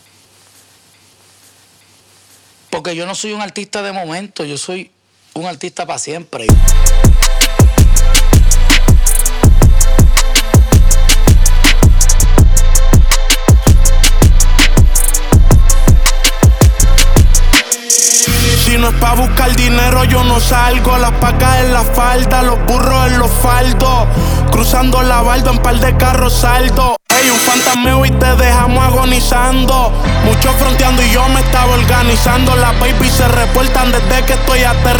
Жанр: Латиноамериканская музыка
# Urbano latino